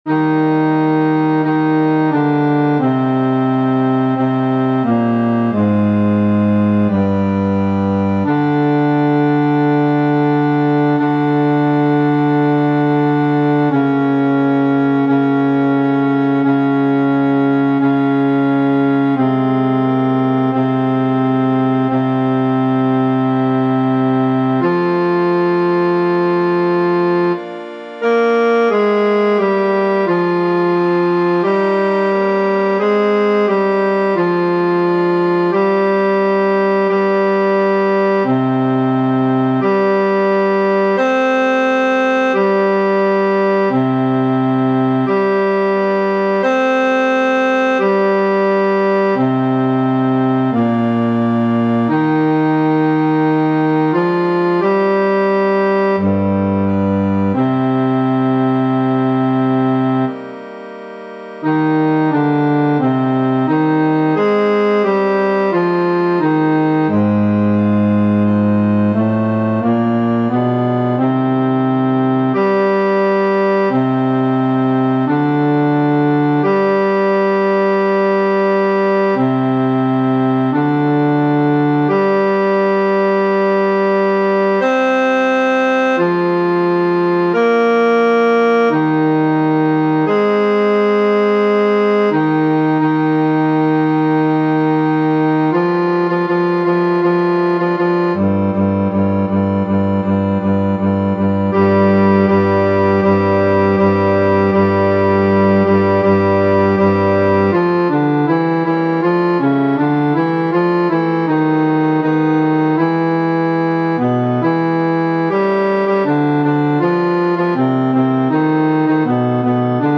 Pisen_ceska-Bas.mp3